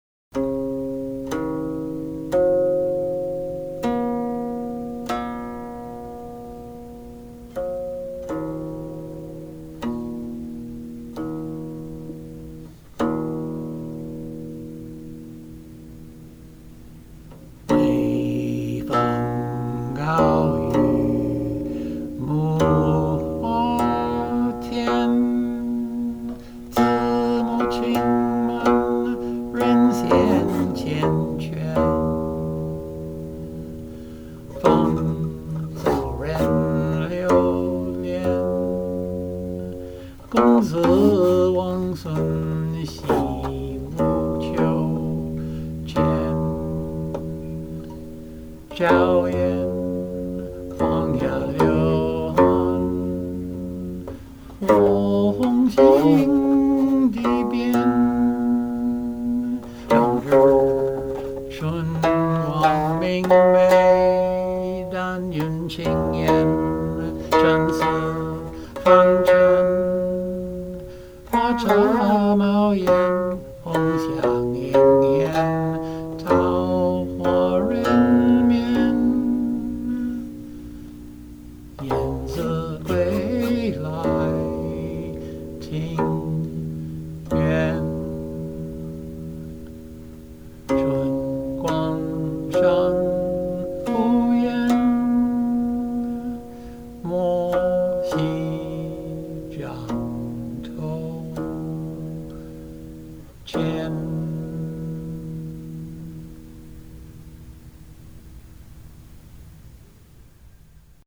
These diao yi are usually called modal preludes because many of them seem clearly designed to go either with specific longer pieces, or groups of pieces in that mode.
This is a largely syllabic setting.